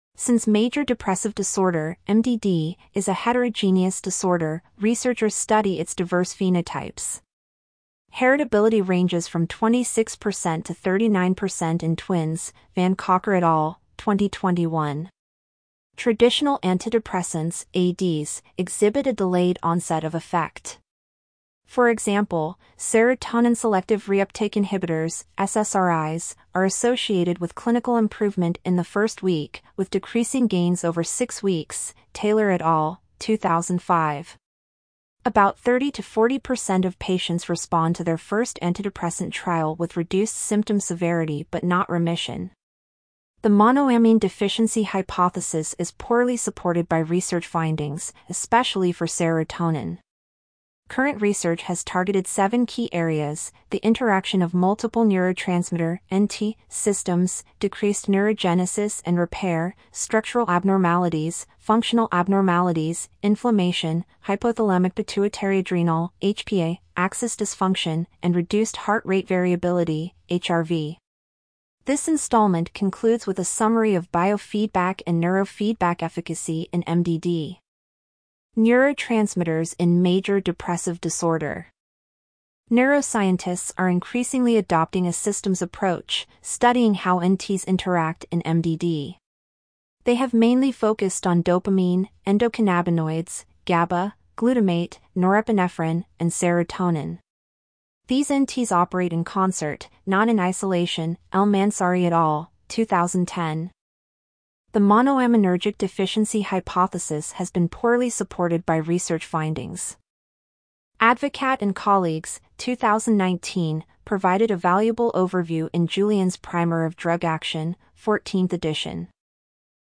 Click on our narrator icon to listen to this post.